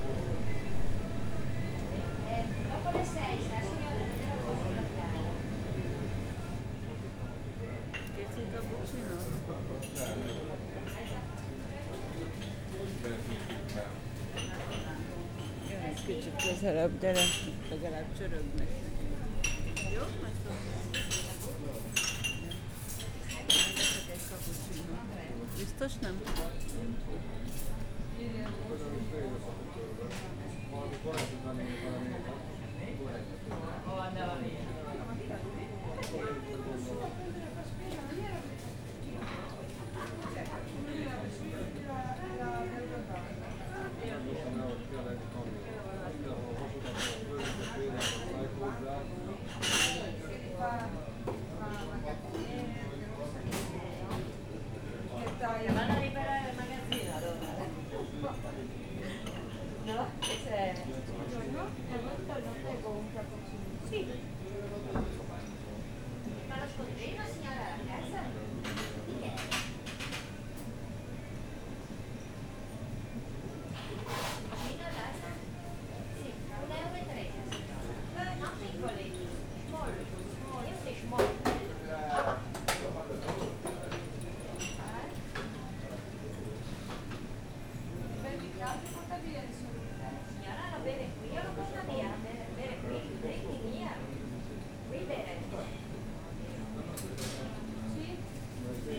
zacskozorges_mercedes_sds01.47.WAV